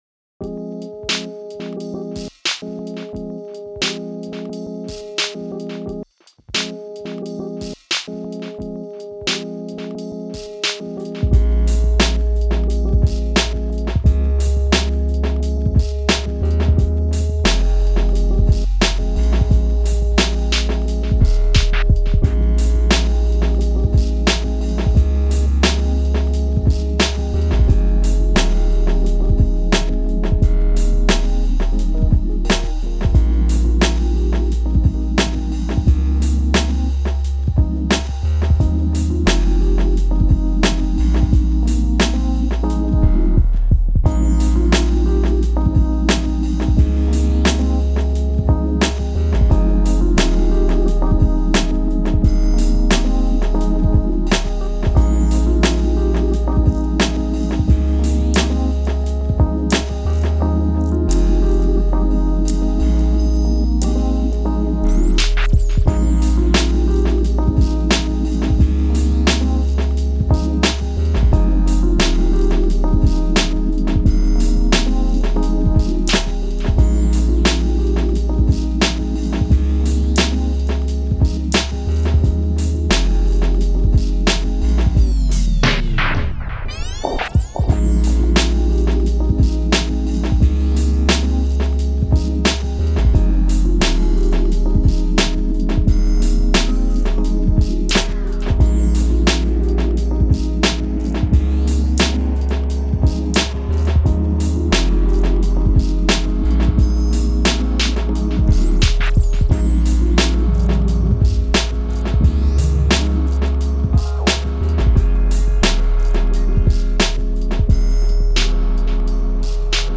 Jammed out the arrangement with a single USB cable into my laptop/daw.
Short samples looping with BRR and SRR sound great.
That trick is to thank for little aliasing sweep sound that starts up around 45 seconds.